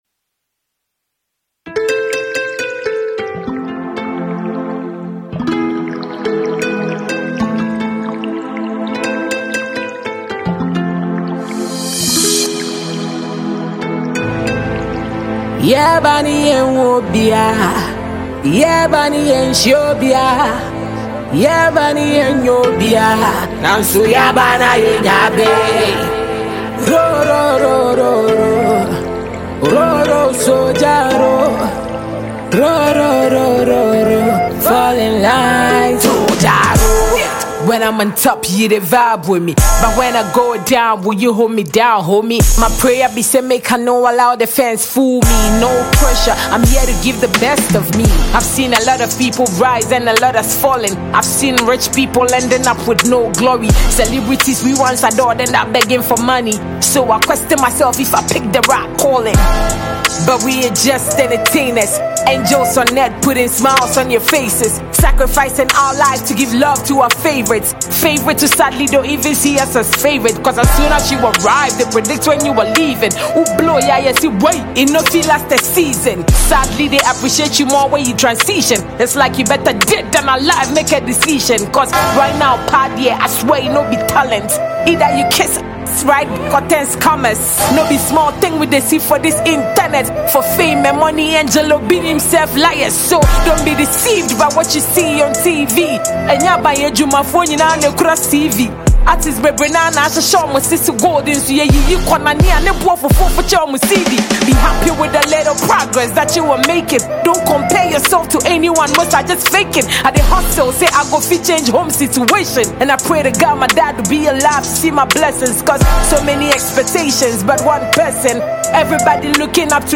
Ghana Music Music
Ghanaian hardcore female rapper